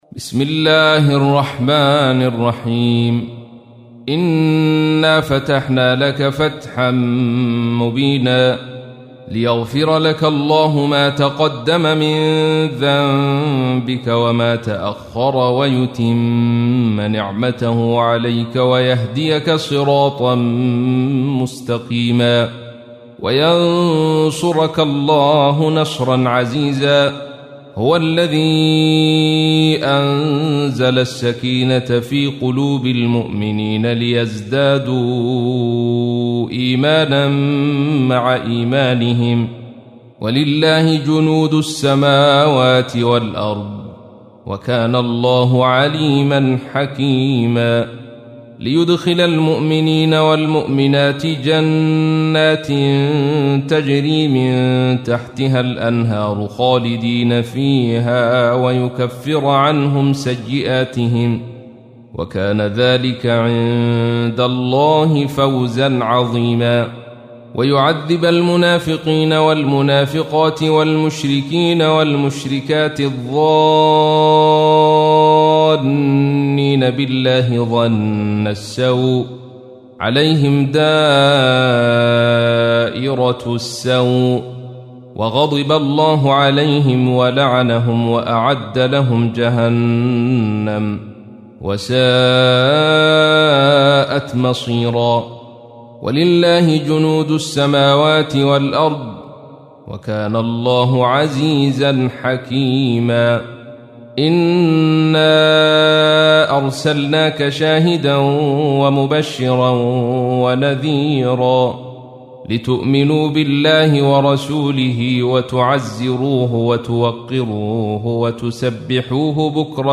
تحميل : 48. سورة الفتح / القارئ عبد الرشيد صوفي / القرآن الكريم / موقع يا حسين